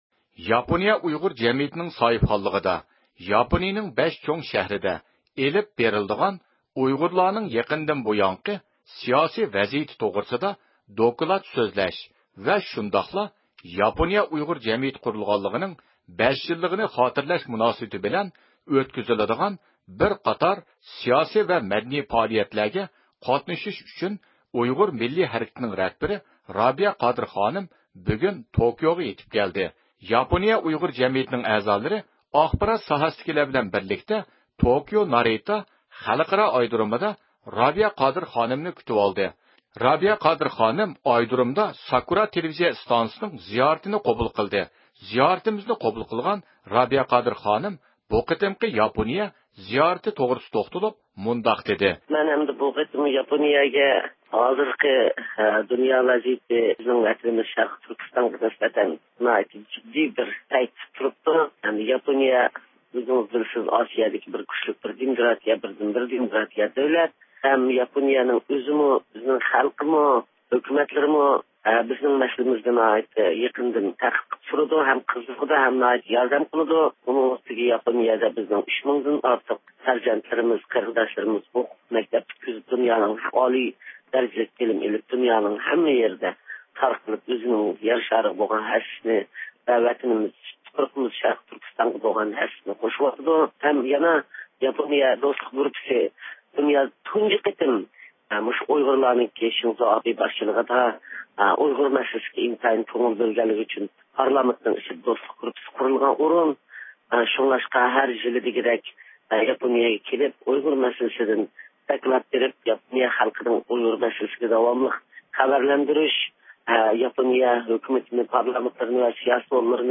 رابىيە قادىر خانىم ئايرودرومدا « ساكۇرا» تېلېۋىزىيە ئىستانسىسىنىڭ زىيارىتىنى قوبۇل قىلدى.